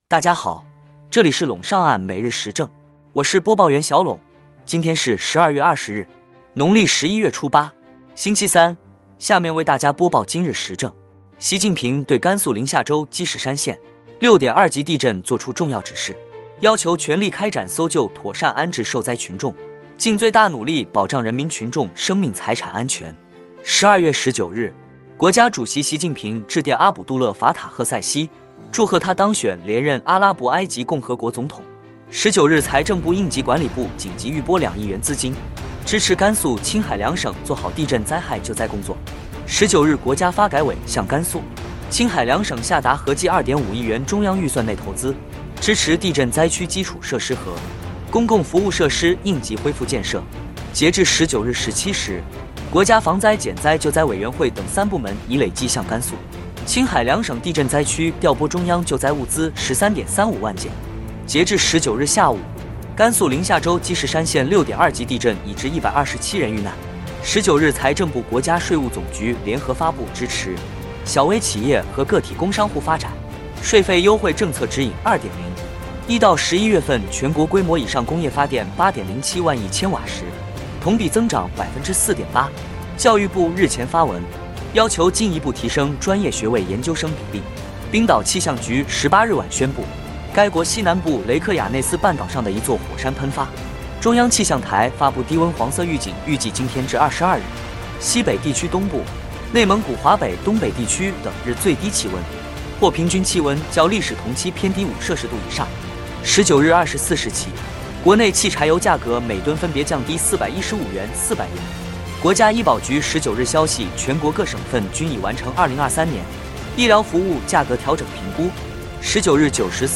▼今日时政语音版▼